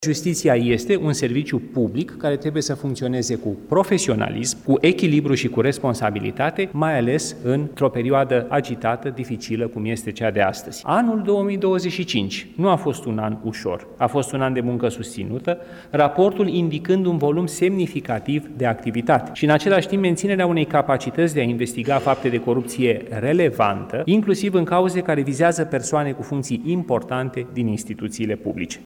Ministrul Justiției, Radu Marinescu, a descris această perioadă drept agitată și dificilă pentru sistemul judiciar din România. Aflat la prezentarea raportului Direcției Naționale Anticorupție pe anul 2025, oficialul a motivat – cu statistici – că activitatea procurorilor a devenit mai eficientă.